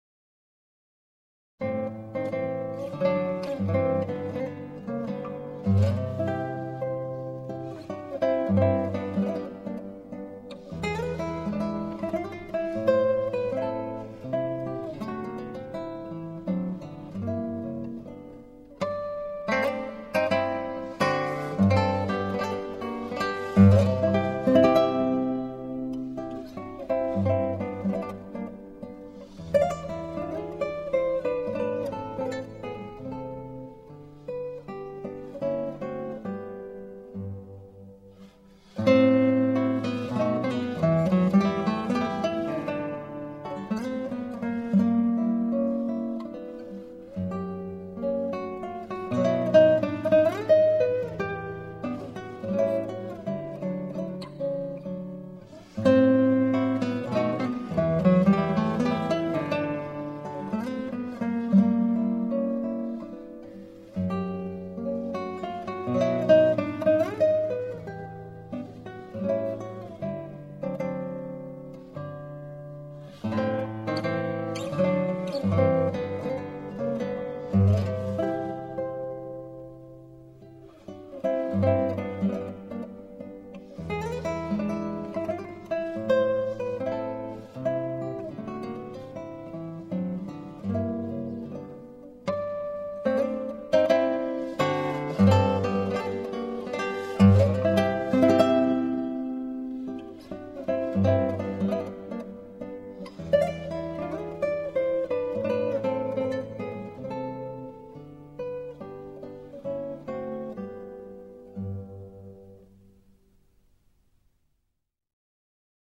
0205-吉他名曲马祖卡独奏.mp3